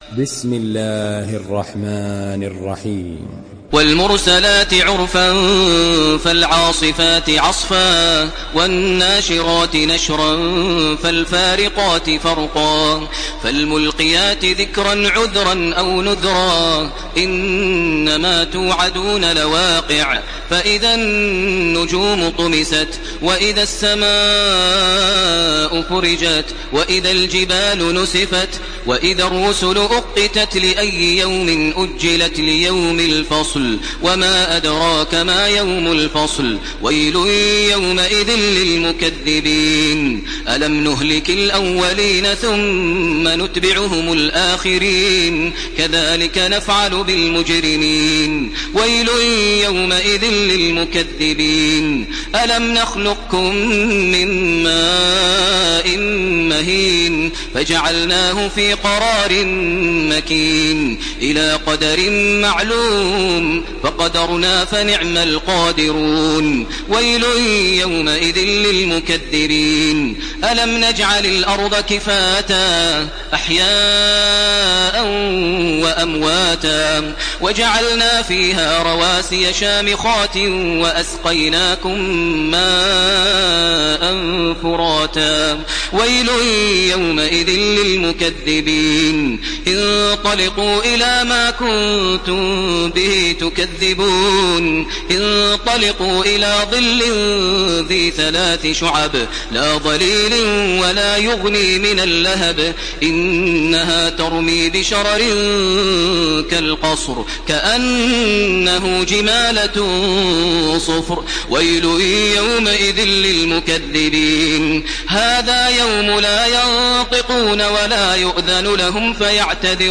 Surah Al-Mursalat MP3 in the Voice of Makkah Taraweeh 1431 in Hafs Narration
Murattal